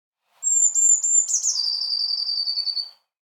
Die Blaumeise
Blaumeise_audio.mp3